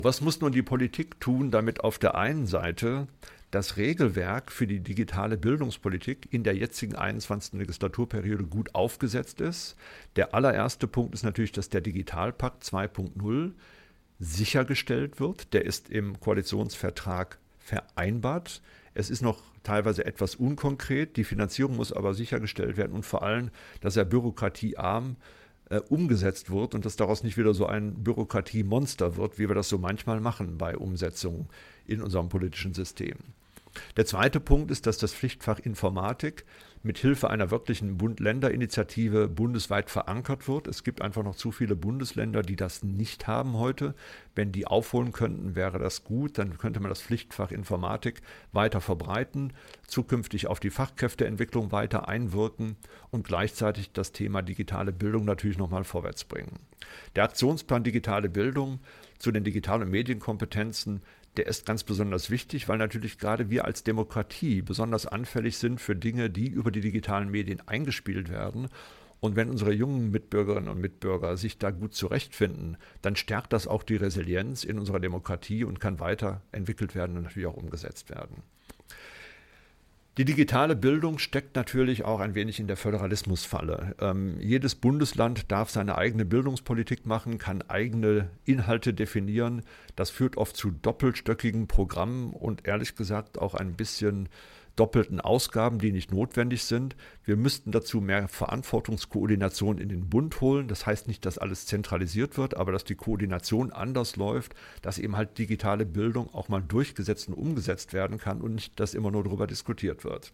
Mitschnitte der Pressekonferenz
bitkom-pressekonferenz-digitale-schule-2025-politik.mp3